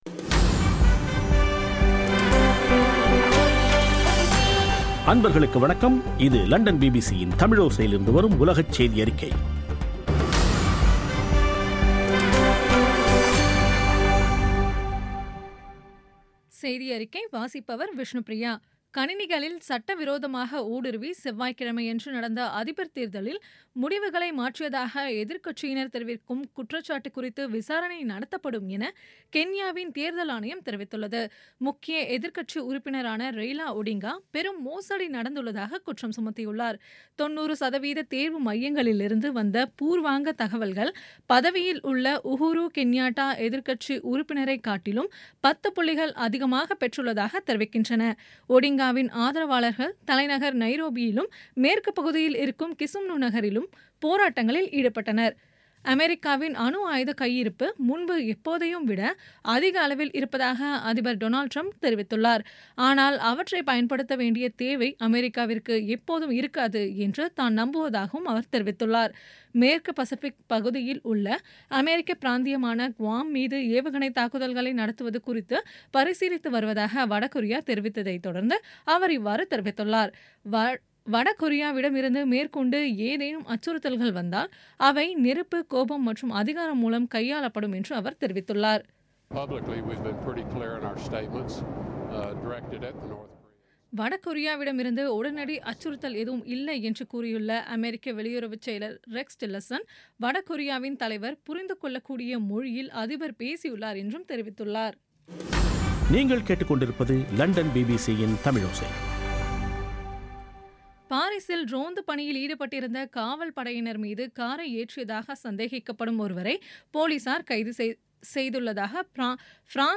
பிபிசி தமிழோசை செய்தியறிக்கை (09/08/2017)